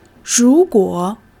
ru2-guo3.mp3